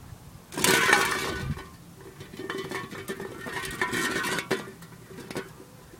罐子 " 滚动式罐子 11
描述：通过沿着混凝土表面滚动各种尺寸和类型的罐头制成的声音。录音设备：第4代iPod touch，使用media.io转换。